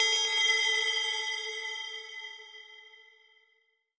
new_tone3.L.wav